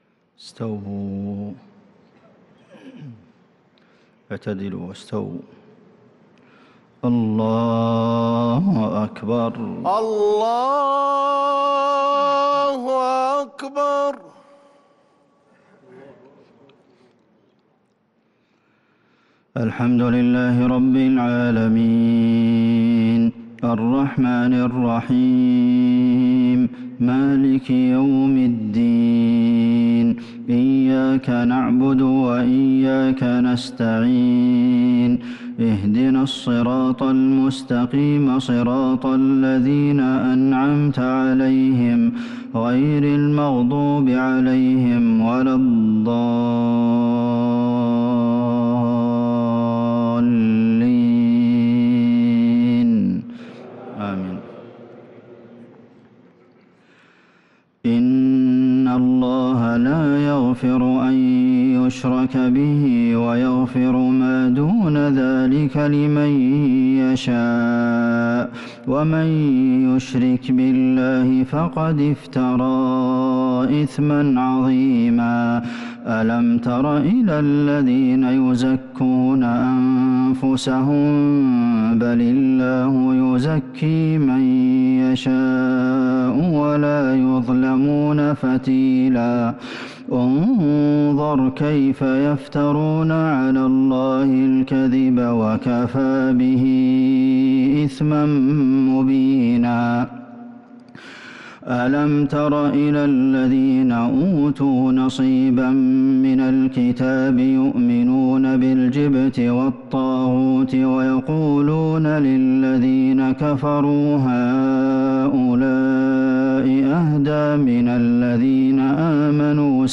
صلاة العشاء للقارئ عبدالمحسن القاسم 3 شوال 1443 هـ
تِلَاوَات الْحَرَمَيْن .